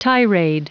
L'accent tombe sur la dernière syllabe: